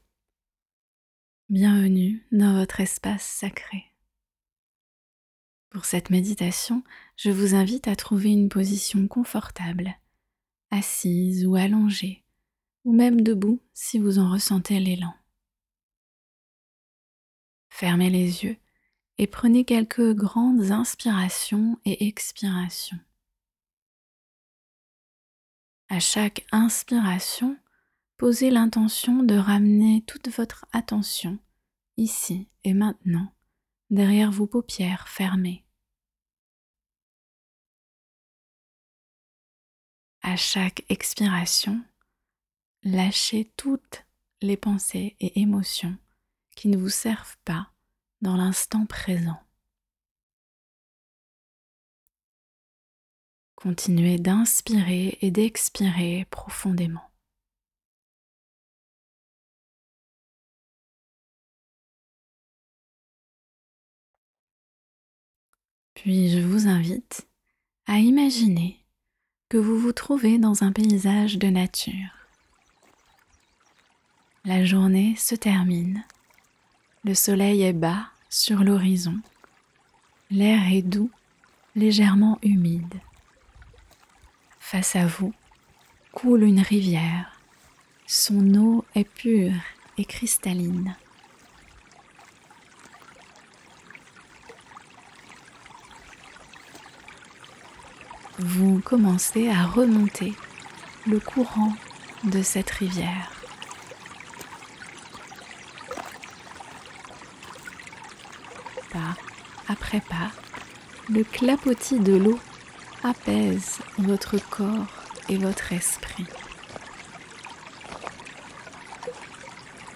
Une méditation guidée à écouter le soir pour libérer les pensées, émotions et énergies accumulées durant votre journée et qui ne vous appartiennent pas. Un voyage avec l’élément eau pour vous décharger et vous alléger de tout ce qui ne vous sert plus.
meditation-du-soir-clore-sa-journee.mp3